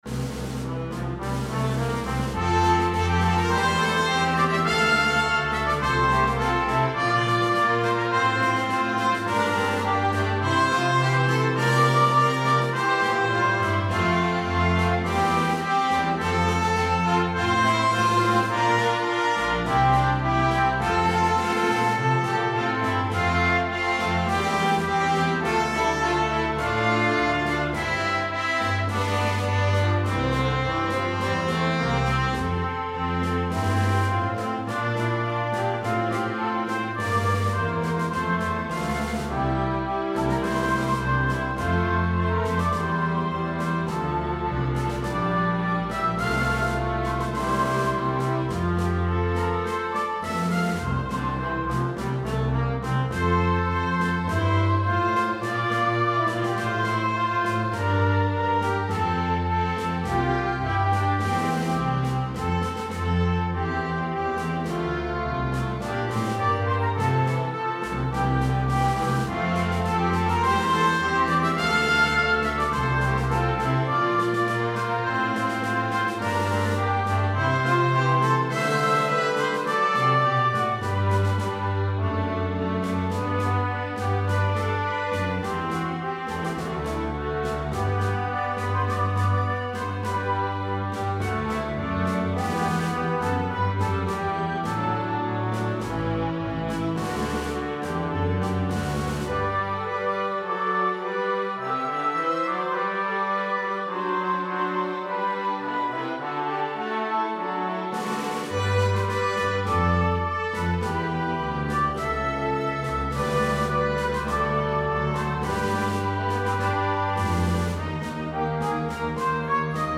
Arranged in 4 parts, fully orchestrated.